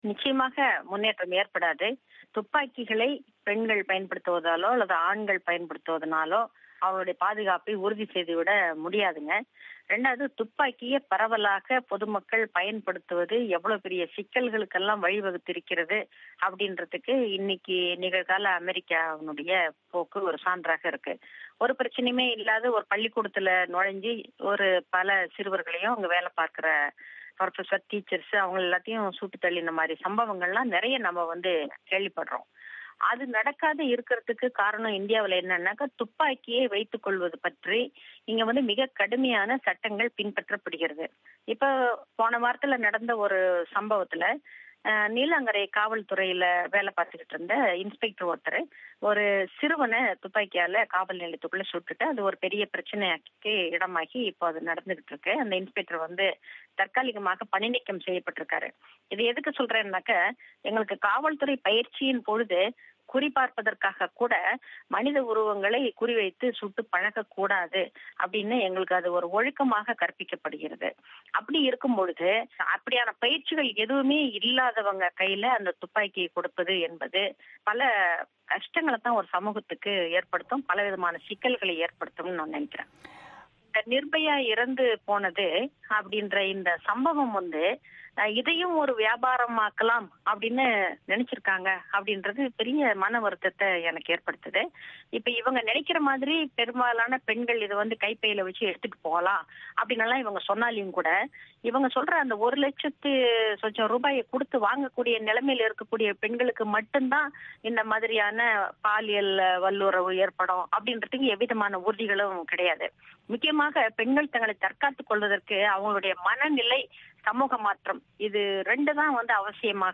ஆனால் பயிற்சியற்றவர்களிடம் ஆயுதங்கள் இருப்பதால் எதிர்மரையான விளைவுகளே ஏற்படும் என்கிறார் ஒய்வு பெற்ற காவல்துறை அதிகாரி ஜி.திலகவதி. அவரின் செவ்வியை நேயர்கள் இங்கு கேட்கலாம்